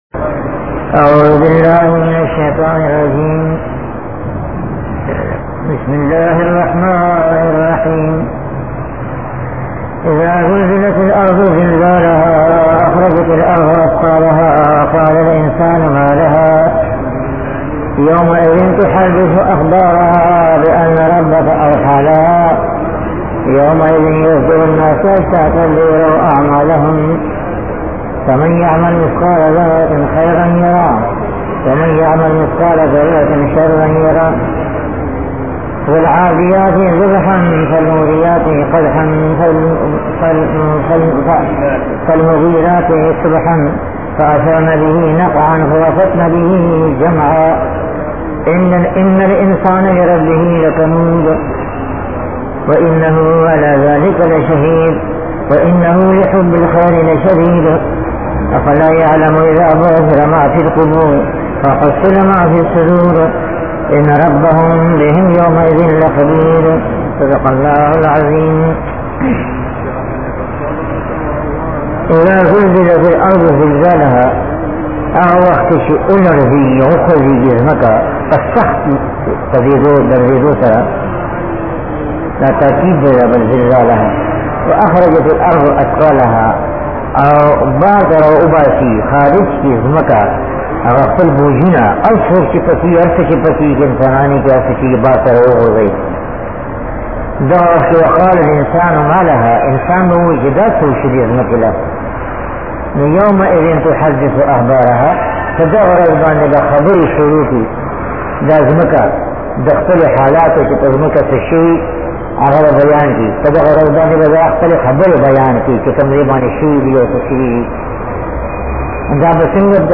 TAFSEER OF THE HOLY QURAN
SABAQ NO 964 SURAH ZILZAAL--ADIYAAT.mp3